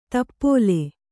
♪ tappōle